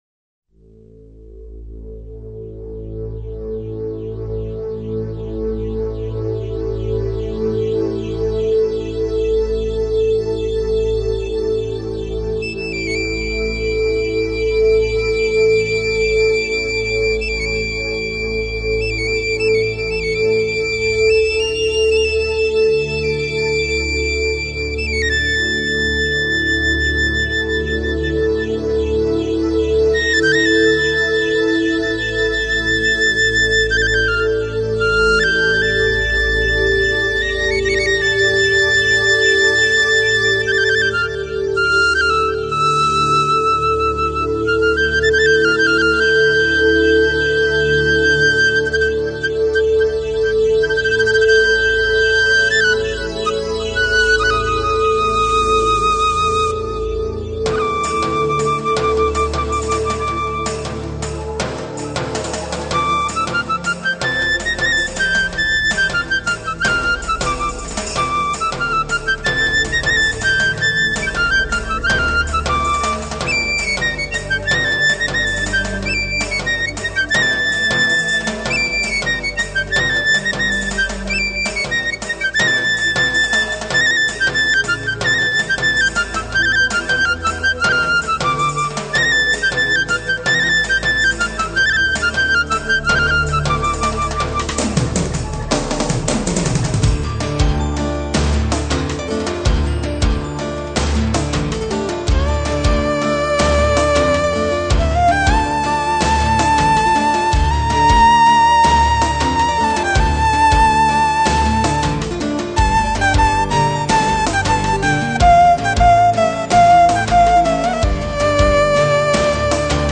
因此總愛在巴烏、竹筒琴奏出的迷人音韻中，跳起婀娜的孔雀舞；
高原上的塔吉克人，以鷹笛吹出族人對鷹的崇敬.....